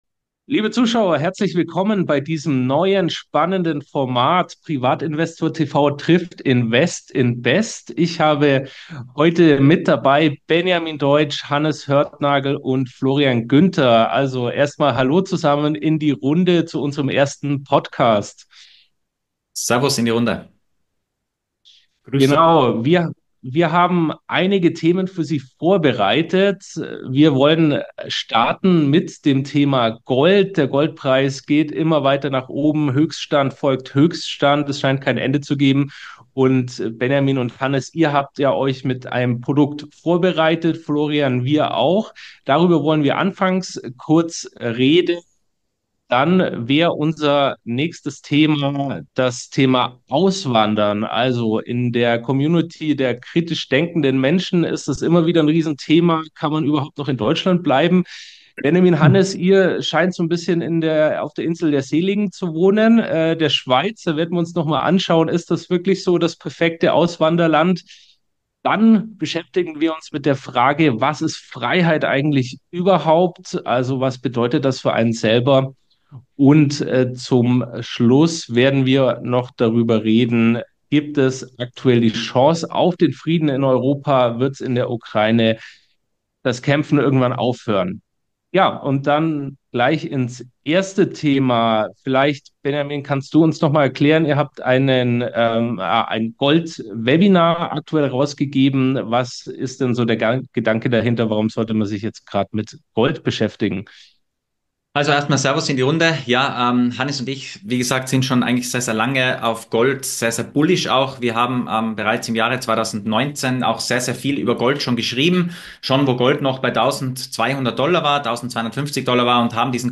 Beschreibung vor 1 Jahr Gold auf Rekordhoch, globale Konflikte und die Frage nach wahrer Freiheit – ein spannendes Roundtable-Gespräch mit Top-Experten!